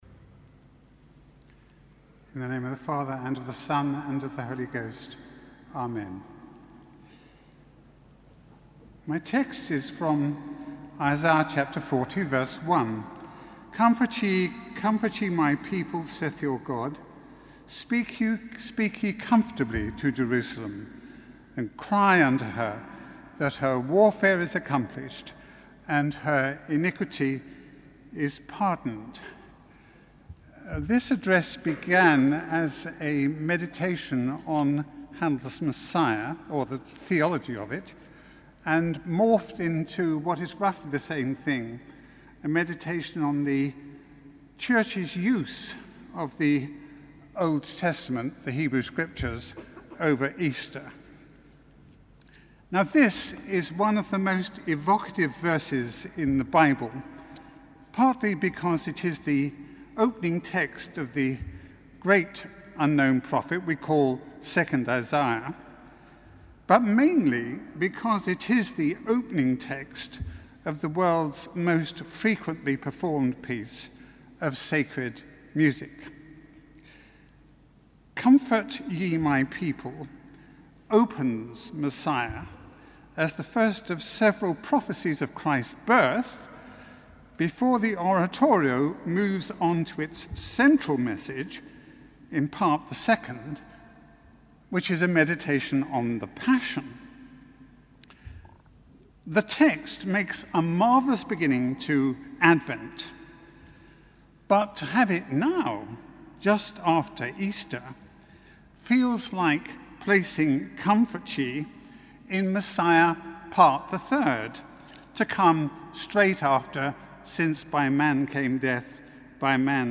Sermon: Mattins - 4 May 2014